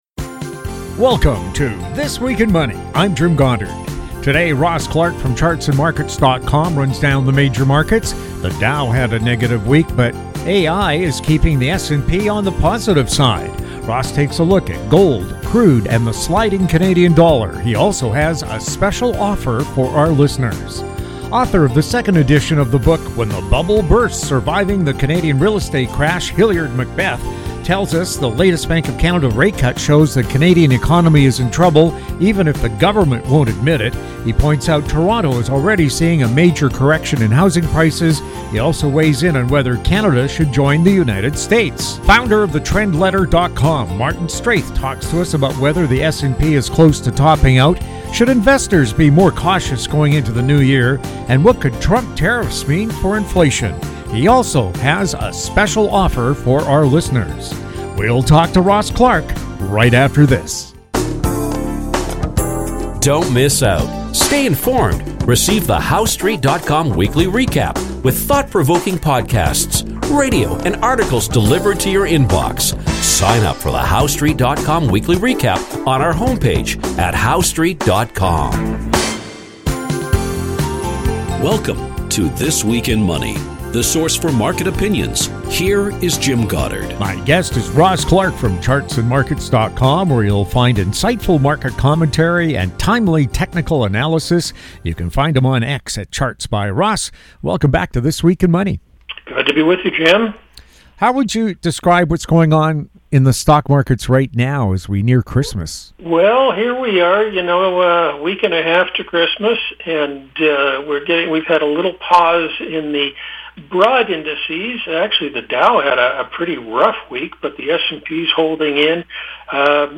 December 14, 2024 | This Week in Money This Week in Money Visit Show Archives This Week in Money presents leading financial news and market commentary from interesting, informative and profound guests. They are some of the financial world's most colorful and controversial thinkers, discussing the markets, economies and more!
New shows air Saturdays on Internet Radio.